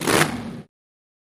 Boat Race Single Boat, Single Engine Rev